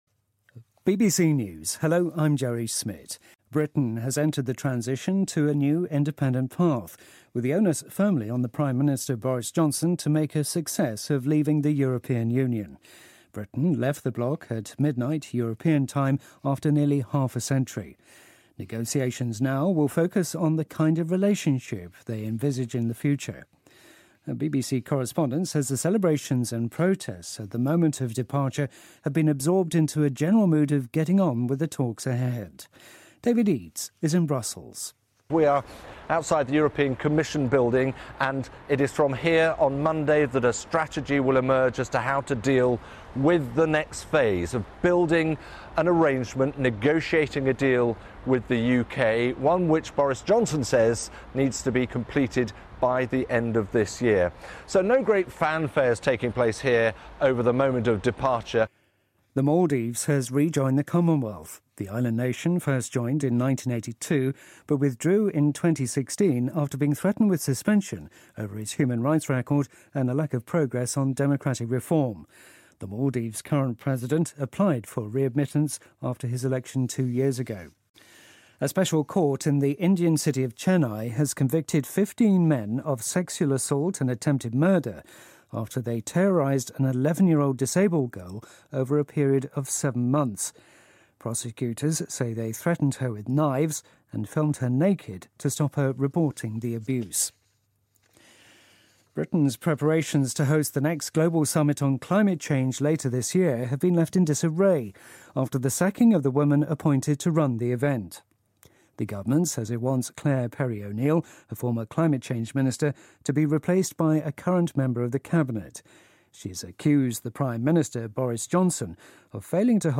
News
英音听力讲解:马尔代夫决定重新加入英联邦